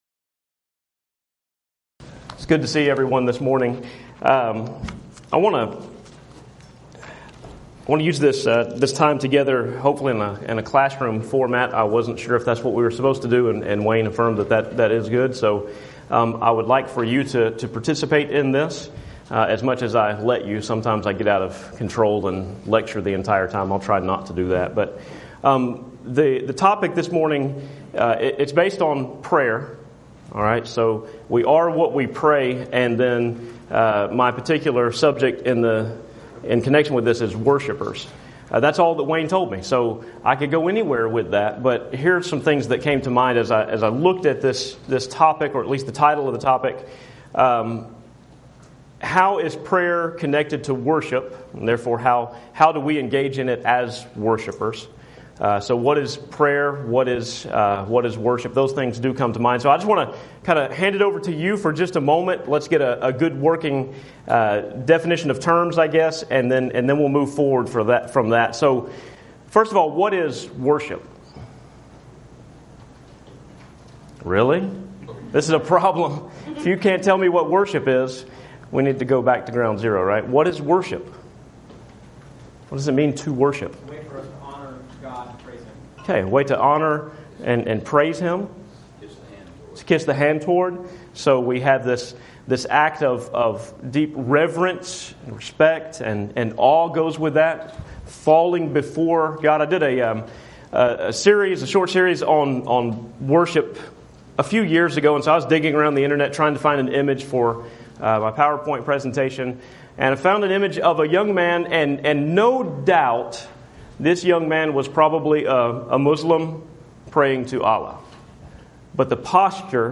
Event: 2017 Focal Point
Preacher's Workshop